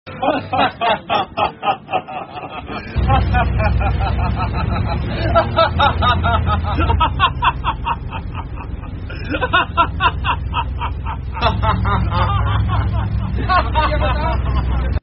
Two-guys-laughing-Meme-sound-effect.mp3